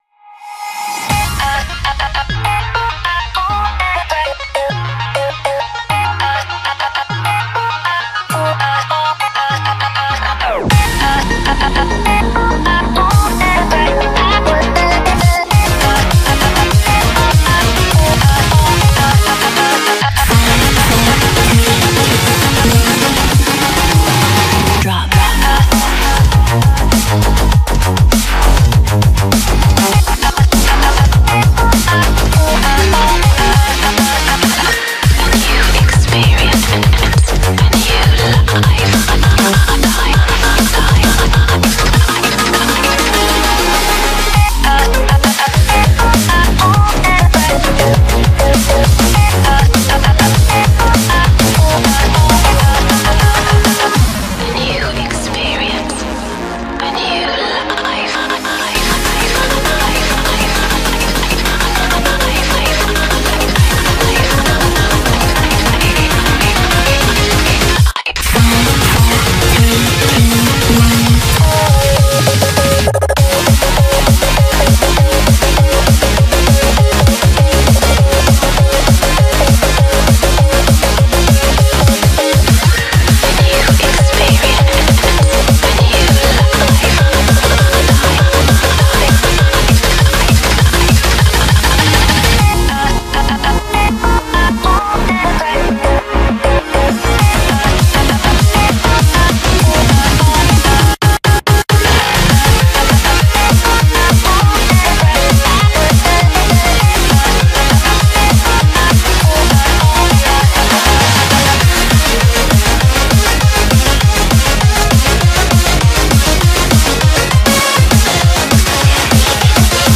BPM100-400
Audio QualityLine Out